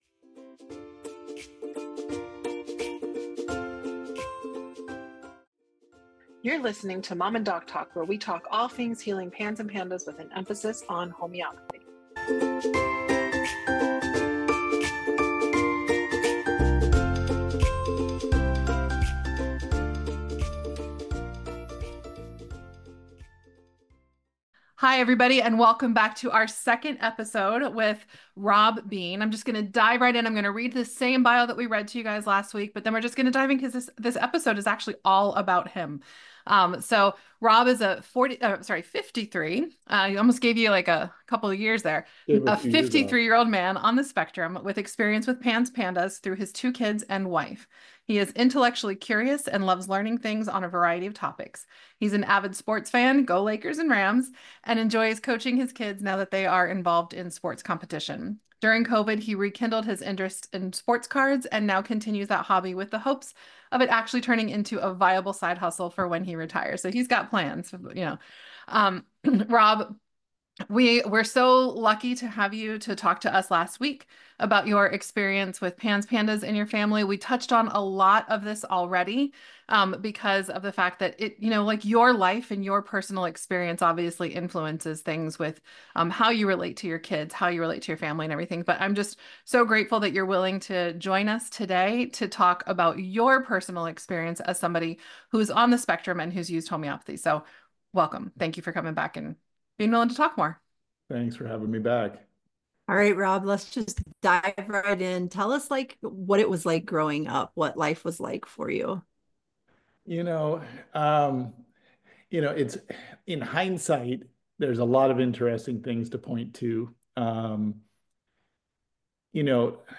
This is a heartfelt and eye-opening conversation you won’t want to miss!